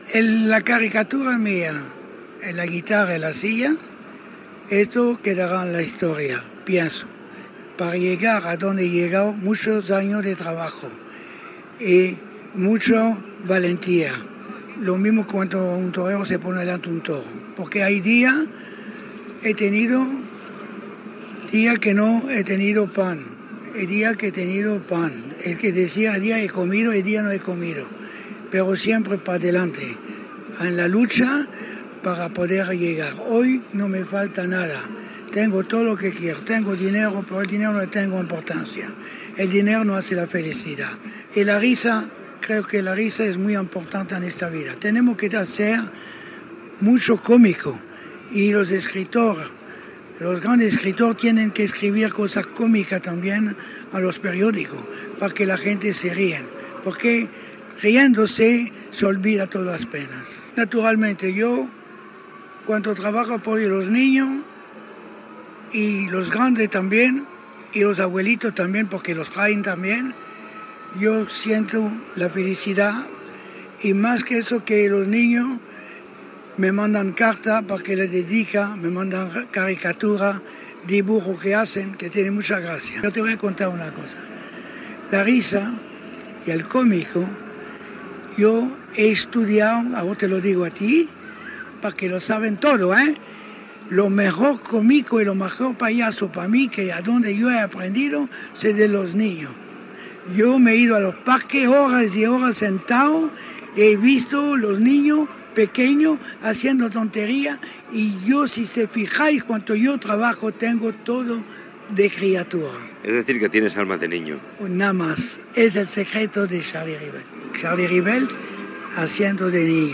Respostes de Charlie Rivel, (Josep Andreu) a la setmana mundial del pallasso celebrada a Barcelona
Entreteniment